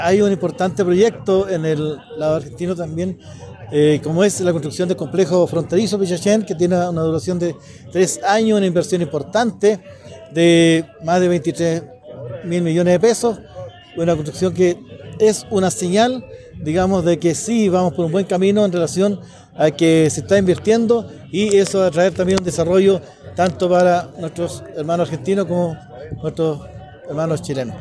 Por su parte, Miguel Abuter, alcalde de la comuna de Antuco, se refirió a la construcción del paso Fronterizo Pichachén que generará beneficios para ambas naciones.